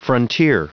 Prononciation du mot frontier en anglais (fichier audio)
Prononciation du mot : frontier